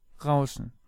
Svetlogorsk (Russian: Светлого́рск; German: Rauschen [ˈʁaʊ̯ʃən]
De-Rauschen.ogg.mp3